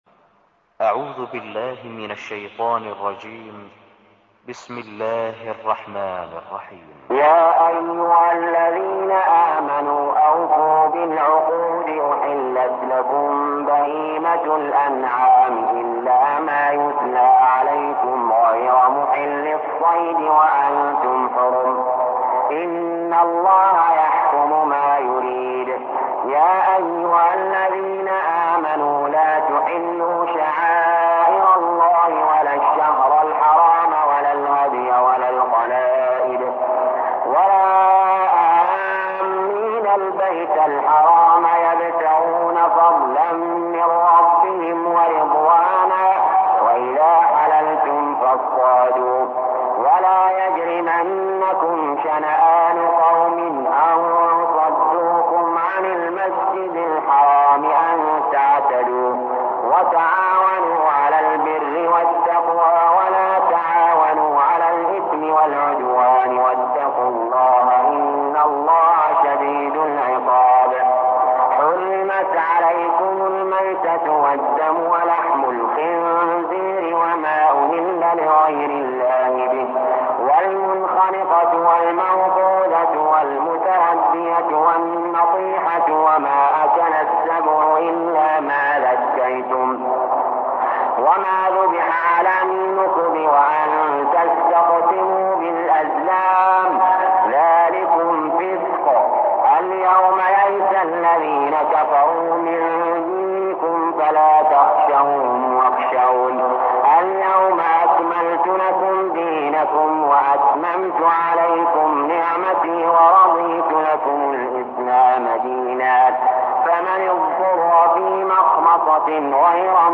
المكان: المسجد الحرام الشيخ: علي جابر رحمه الله علي جابر رحمه الله المائدة